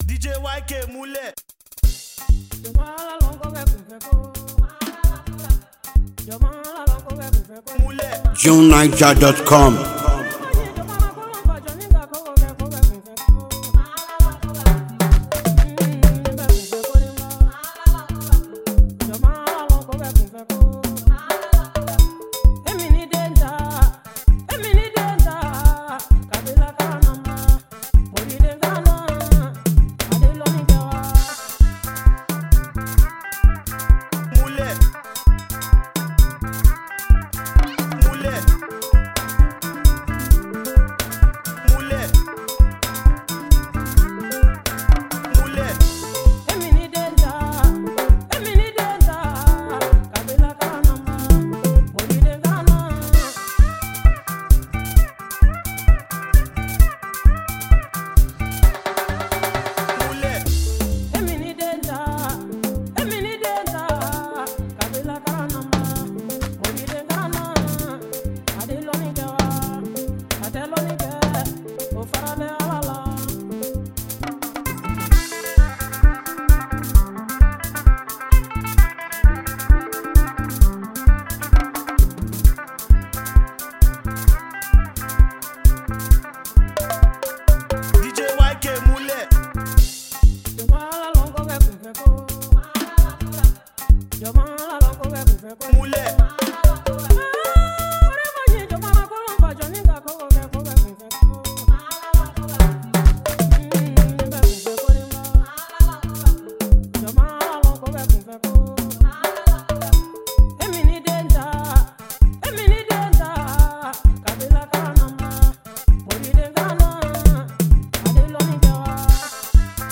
is a lively and thrilling song
Afrobeat and street-pop